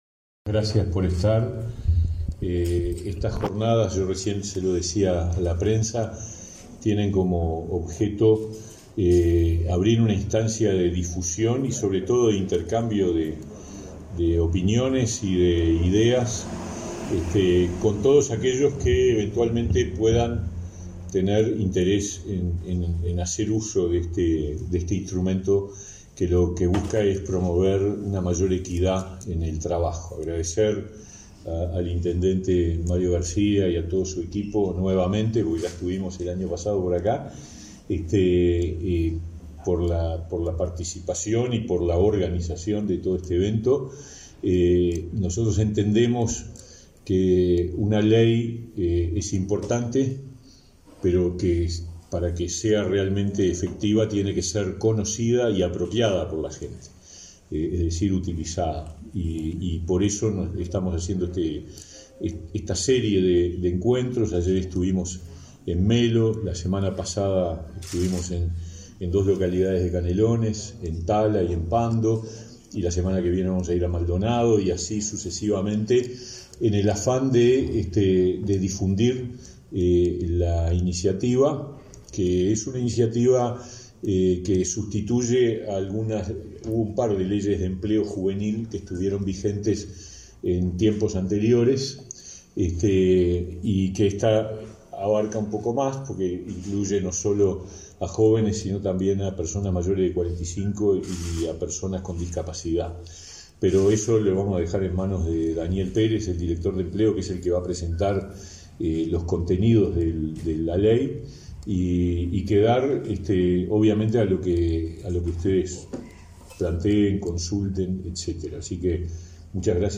Palabras del ministro de Trabajo y Seguridad Social, Pablo Mieres
El ministro de Trabajo y Seguridad Social, Pablo Mieres, mantuvo, este 8 de setiembre, un encuentro con trabajadores y empleadores de Lavalleja para
mieres.mp3